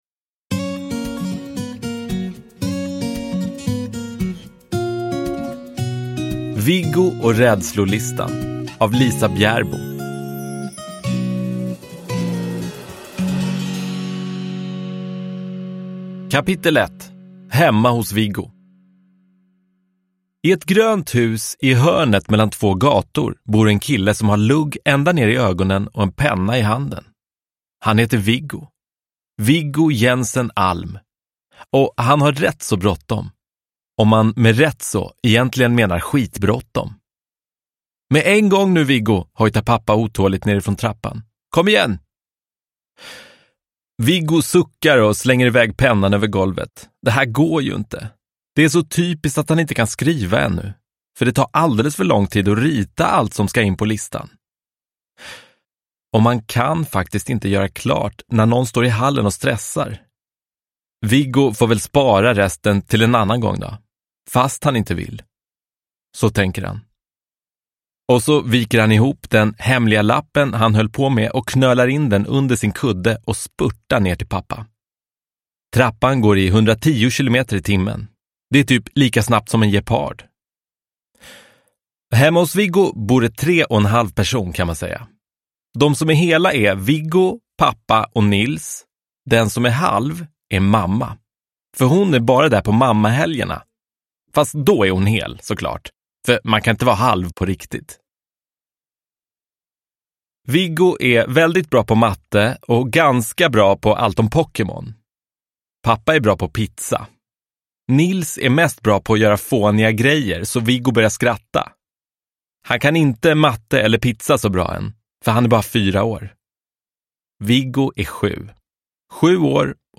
Viggo och rädslolistan – Ljudbok – Laddas ner